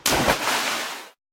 drop.ogg.mp3